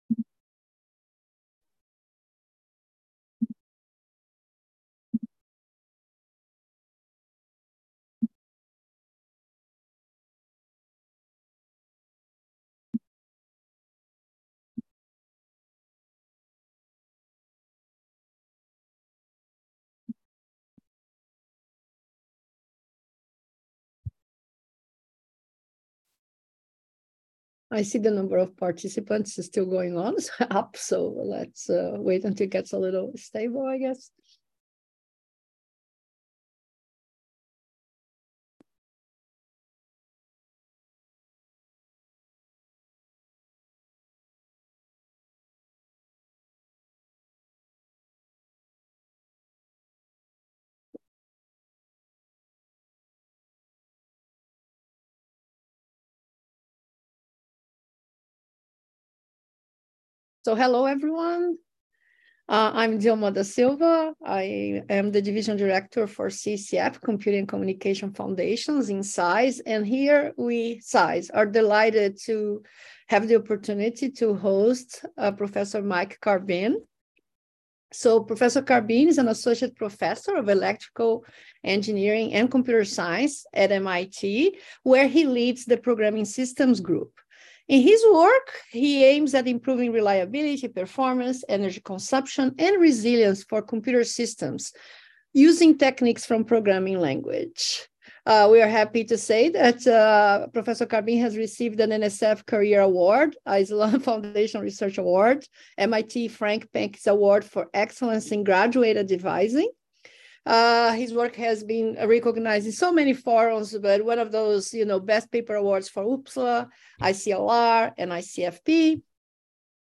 CISE Distinguished Lecture Series